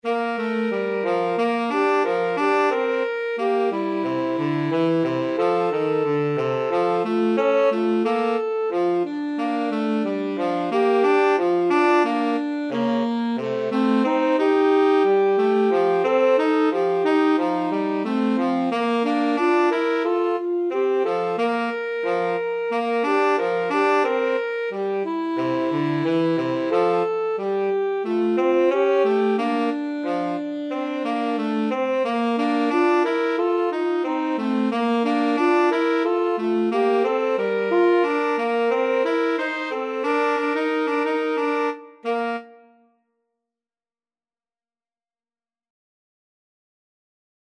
Alto SaxophoneTenor Saxophone
Moderato = c.90
2/2 (View more 2/2 Music)